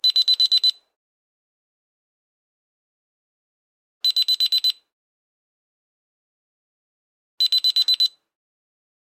随机 "哔哔声报警系统布防待机办公室
描述：蜂鸣报警系统臂备用office.flac
Tag: ARM 系统 报警 待机 办公